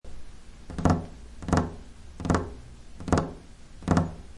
Annoying Fingers Tapping On Desk Sound Effect Download: Instant Soundboard Button